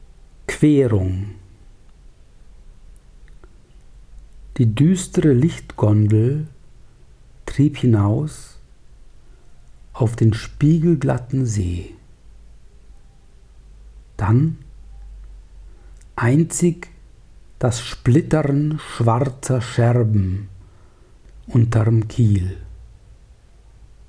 Lesung
Bis wieder Lesungen möglich werden, hier einige gesprochene Verse.